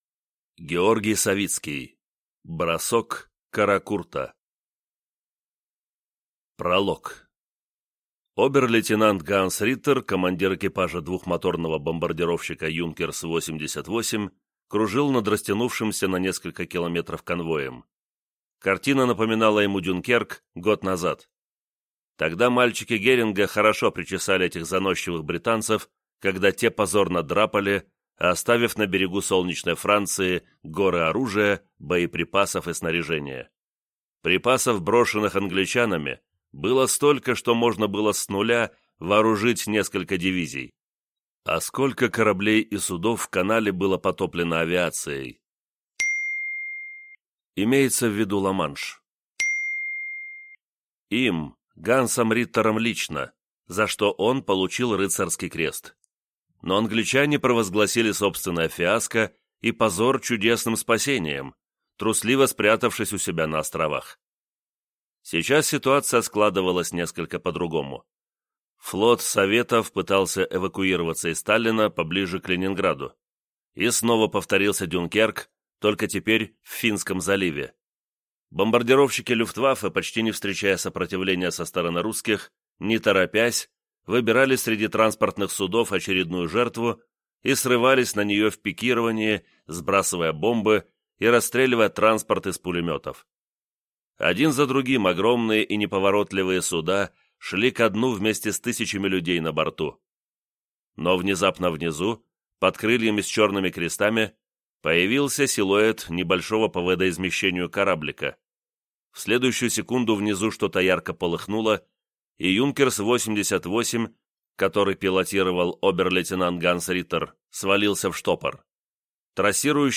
Аудиокнига Бросок «Каракурта» | Библиотека аудиокниг
Прослушать и бесплатно скачать фрагмент аудиокниги